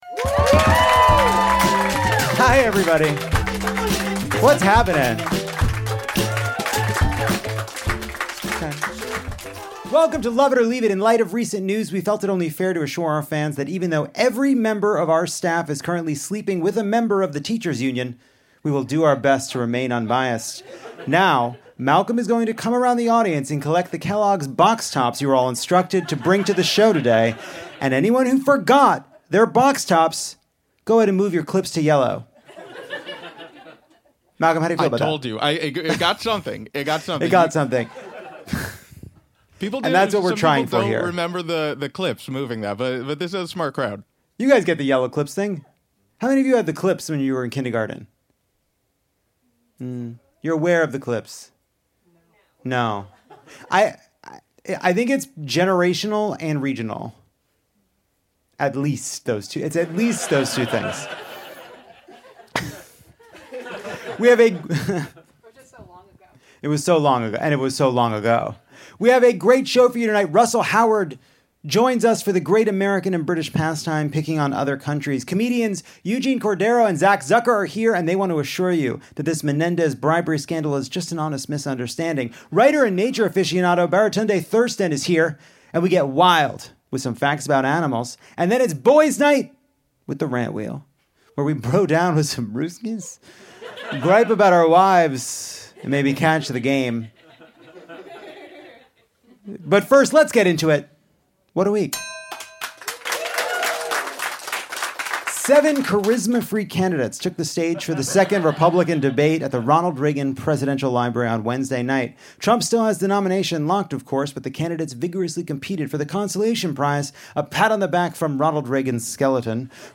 Lovett or Leave It returns to the SiriusXM garage in Los Angeles, home sweet home. Russell Howard explores scandals that could have happened in the US, but didn't. Baratunde Thurston goes full Dr. Doolittle and breaks down the latest from the animal kingdom (and has a whale of a time doing it).